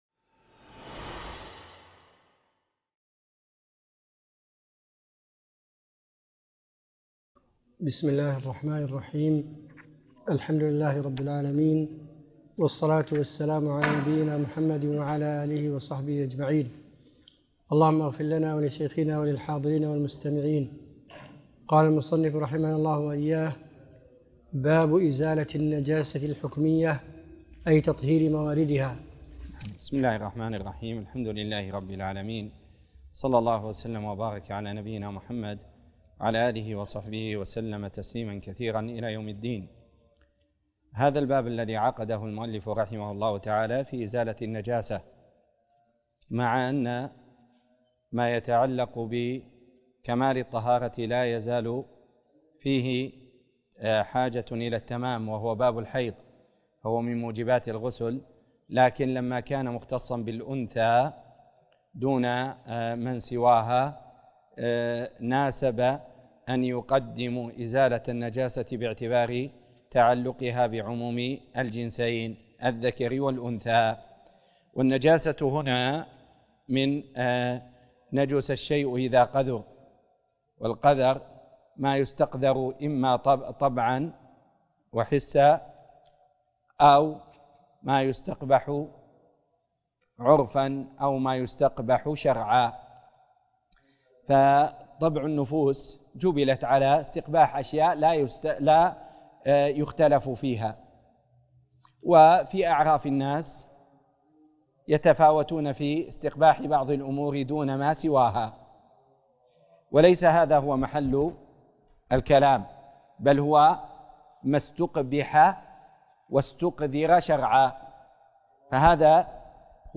الروض المربع - باب إزالة النجاسة - الدرس (10)